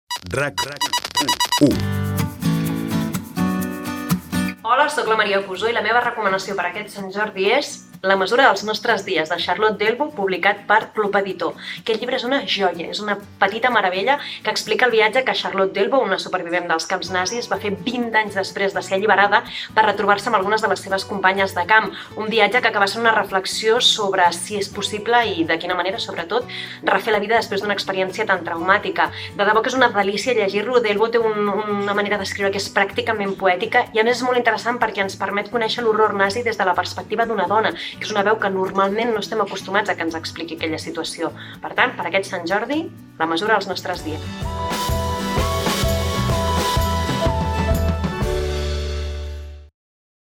Indicatiu de la ràdio i recomanació del llibre "La mesura dels nostres dies" de Charlotte Delbo